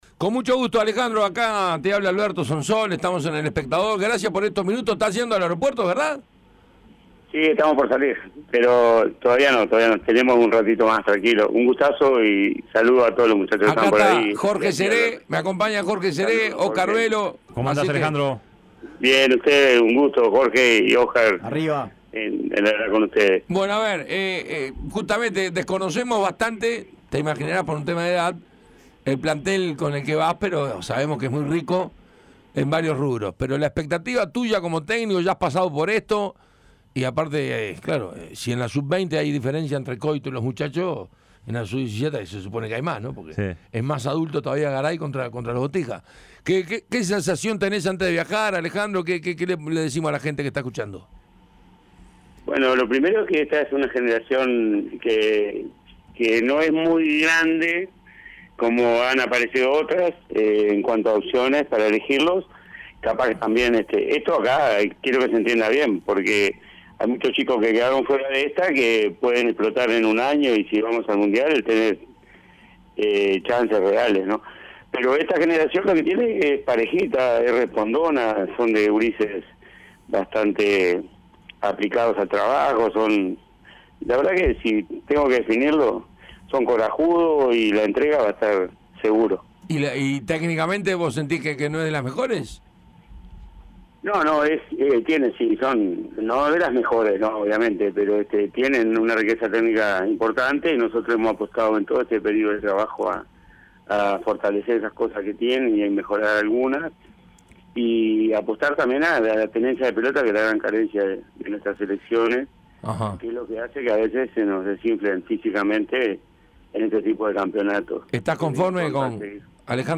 Analizó el plantel, habló de las posibilidades de Uruguay y explicó cuáles son los objetivos. Escuchá la entrevista completa.